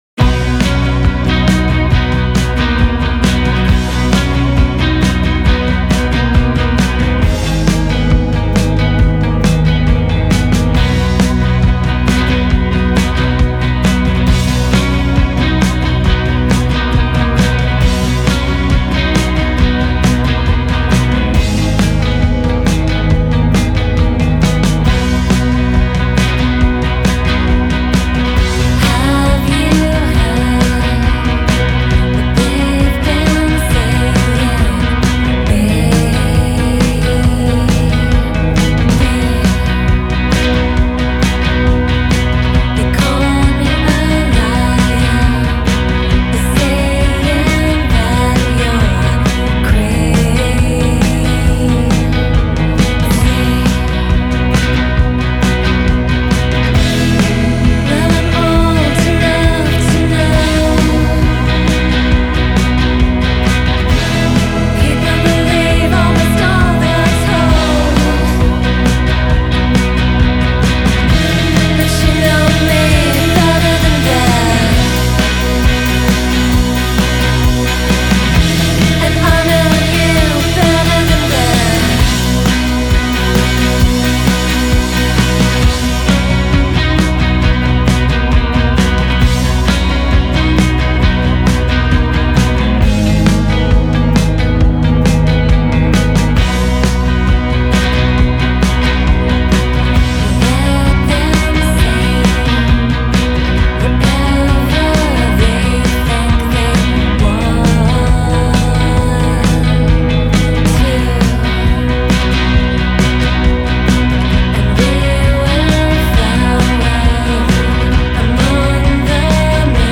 Genre: Indie Pop, Rock, Alternative